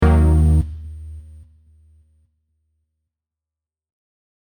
Error 3.mp3